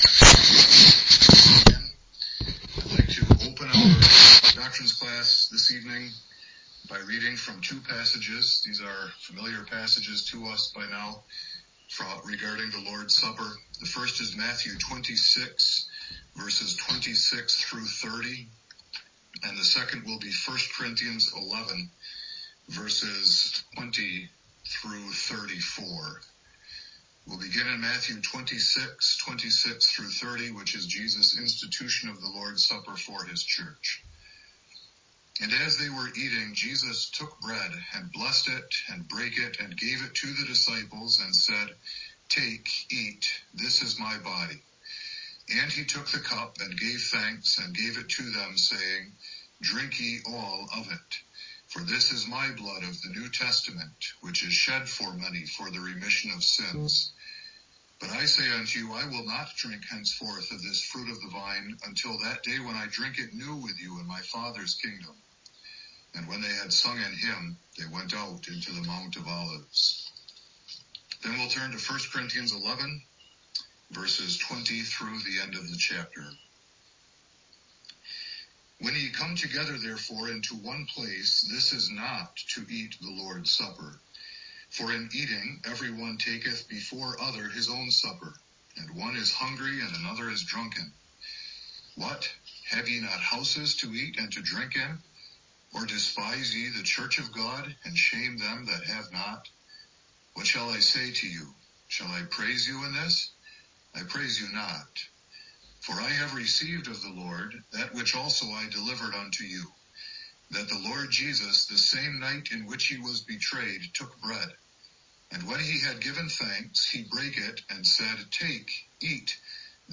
01-28-Lecture_-Theology-and-Practice-of-the-Lords-Supper—Frequency-Means-of-Grace-and-Avoiding-Merit-Calculus1.mp3